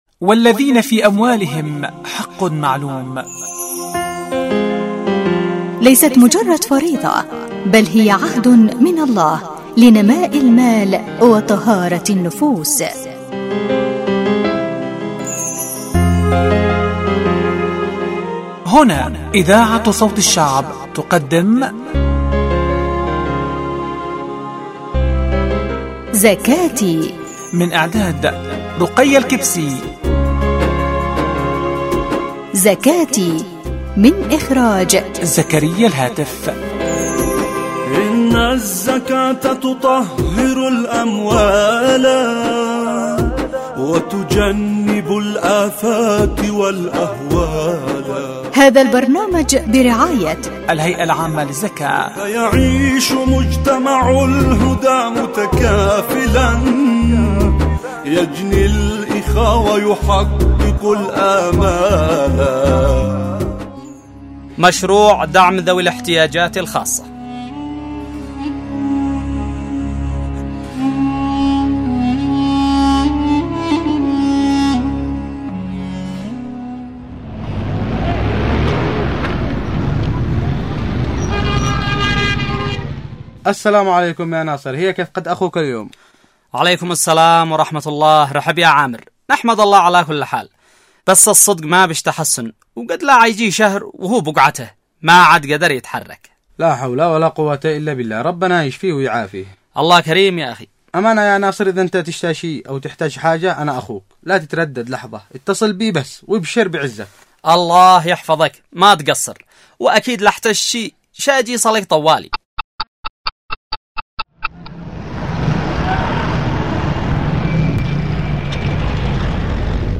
البرامج الحوارية